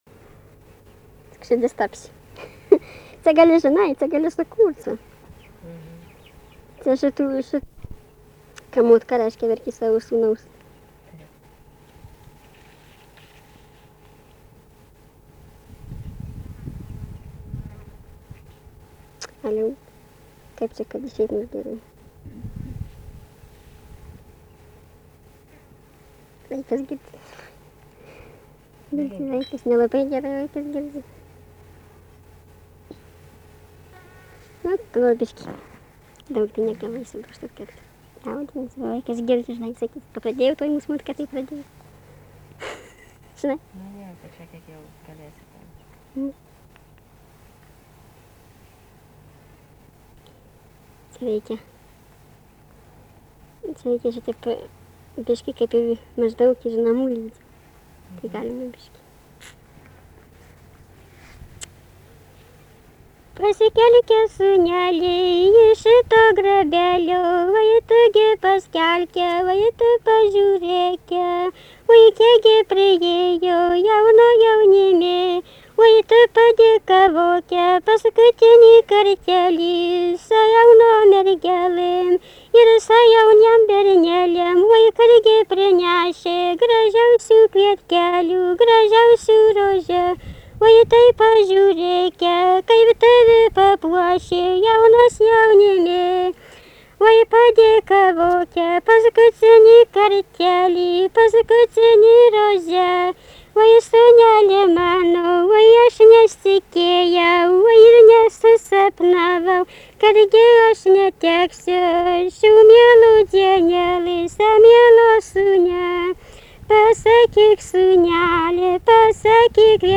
Dalykas, tema daina
Atlikimo pubūdis vokalinis